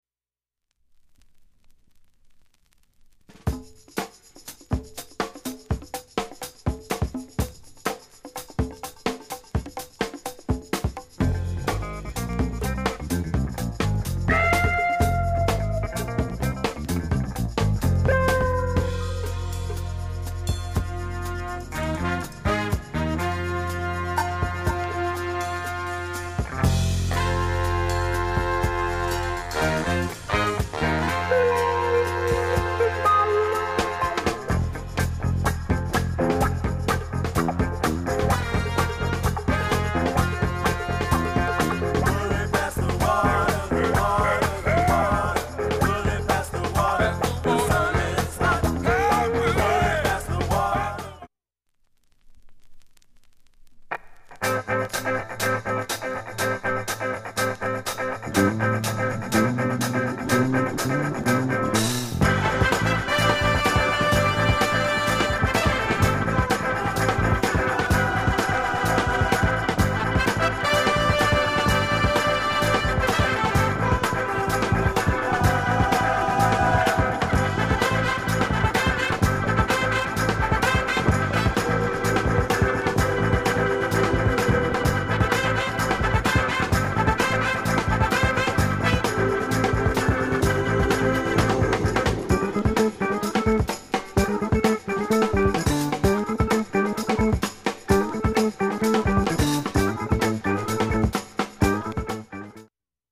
オリジナルオールドスクール・ブレイク